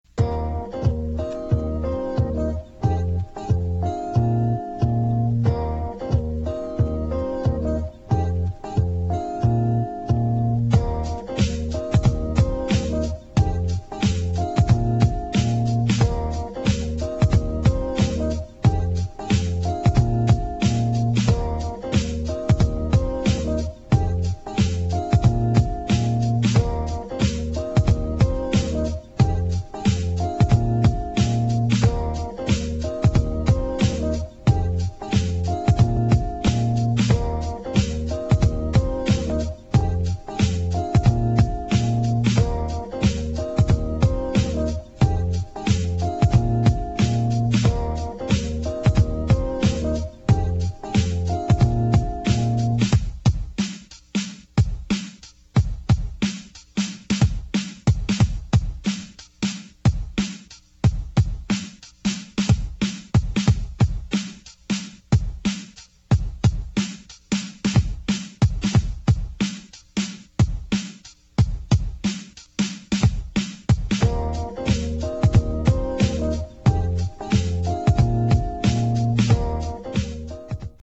[ BREAKS / BREAKBEAT ]